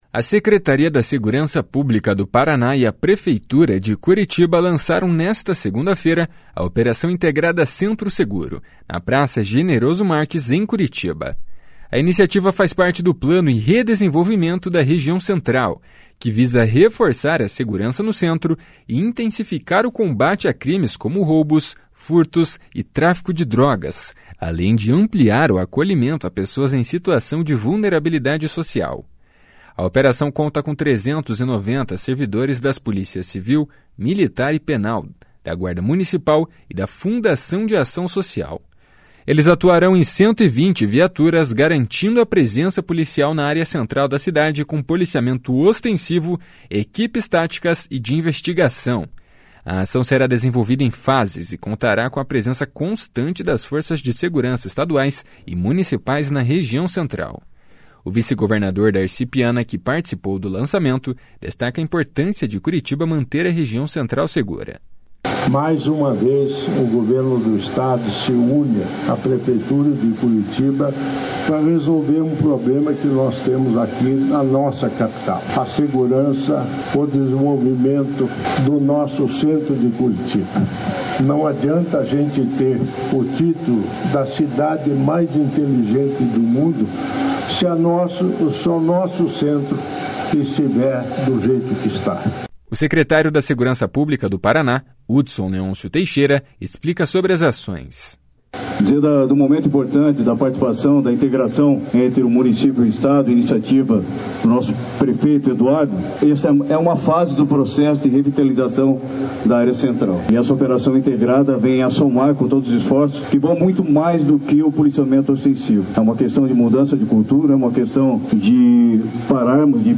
O vice-governador Darci Piana, que participou do lançamento, destaca a importância de Curitiba manter a região central segura.
O secretário da Segurança Pública do Paraná, Hudson Leôncio Teixeira, explica sobre as ações.
O prefeito de Curitiba, Eduardo Pimentel, ressaltou a importância da integração.